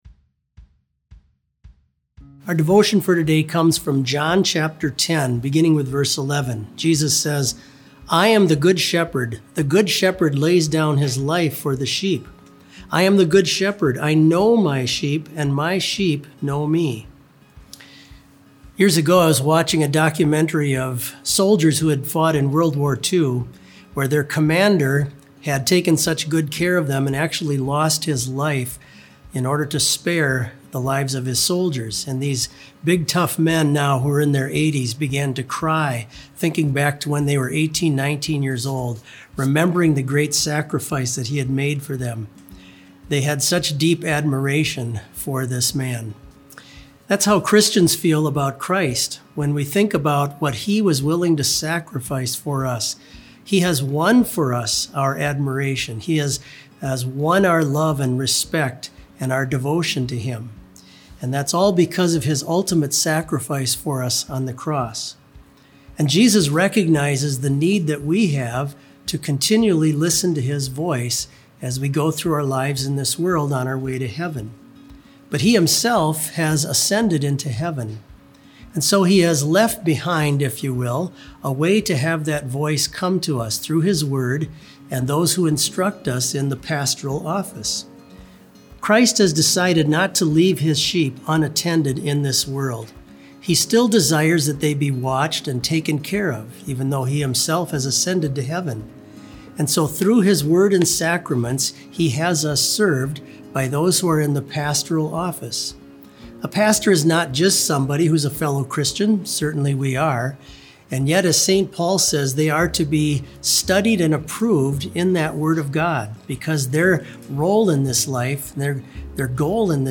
Complete service audio for BLC Devotion - April 28, 2020